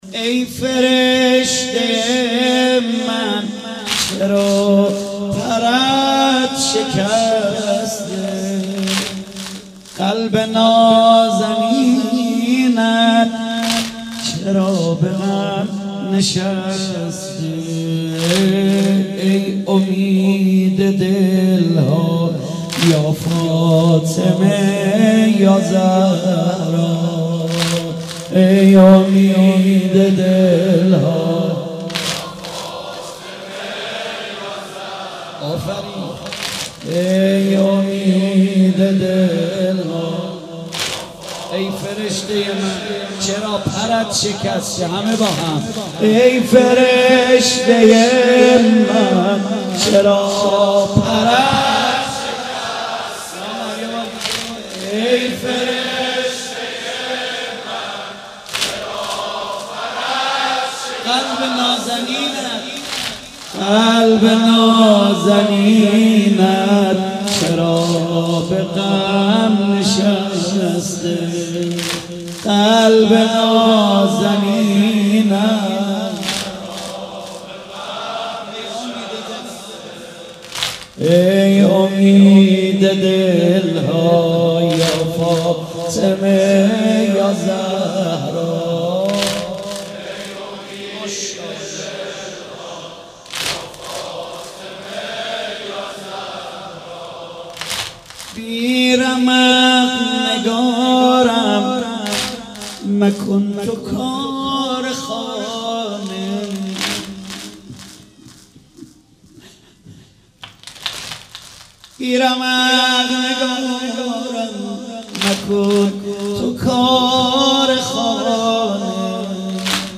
فاطمیه 96 - رفسنجان - واحد - ای فرشته ی من چرا پرت